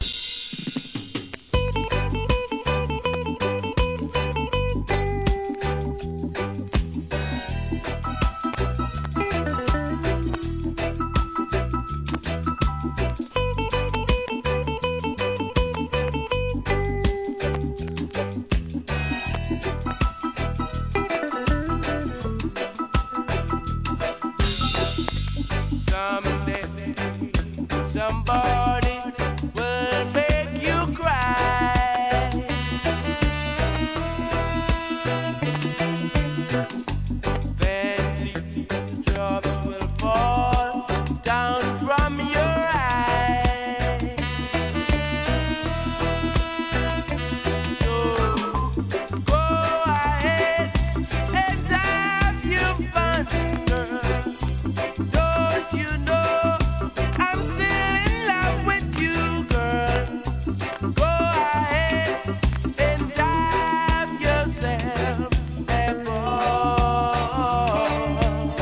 B-C (序盤周期的なノイズ)
REGGAE